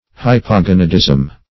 hypogonadism - definition of hypogonadism - synonyms, pronunciation, spelling from Free Dictionary